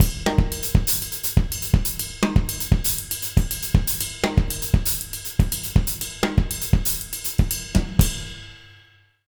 120SALSA04-L.wav